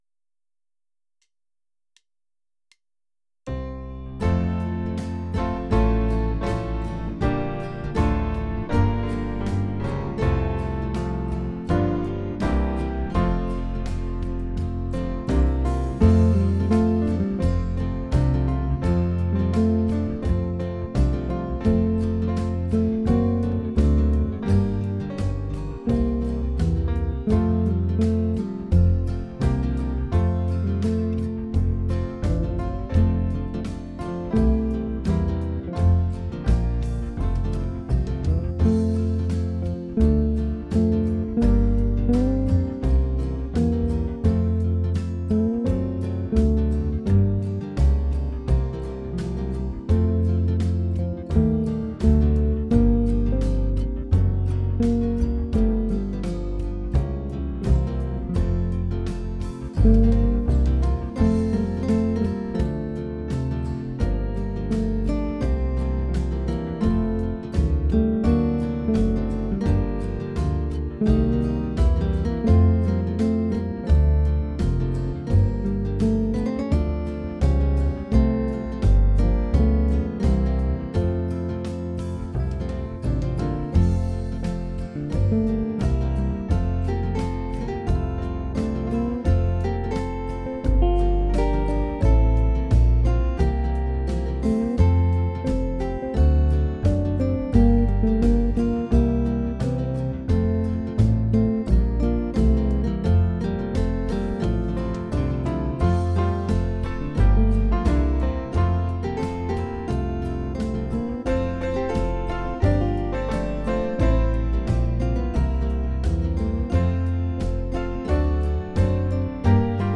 Parts: drums, acoustic string bass, electric bass, 3 solo guitars, 2 rhythm guitars, piano, vibes, and synth pad (5 min.)